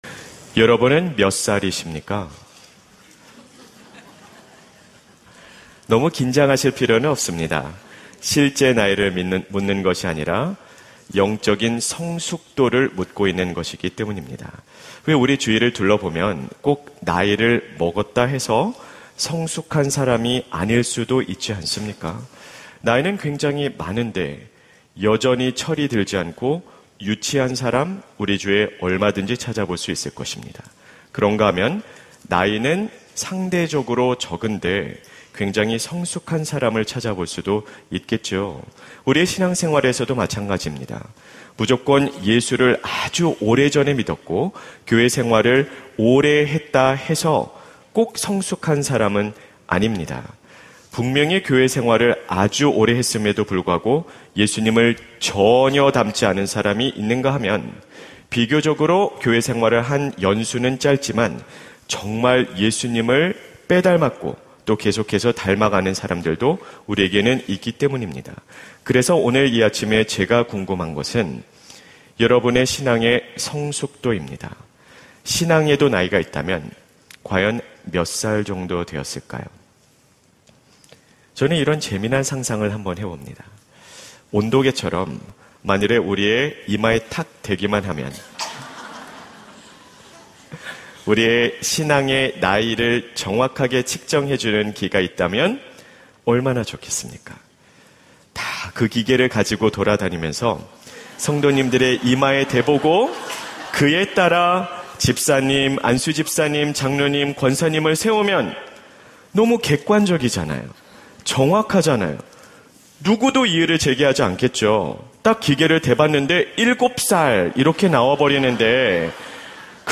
설교 : 수요향수예배 당신은 몇 살입니까? 설교본문 : 요한1서 2:12-14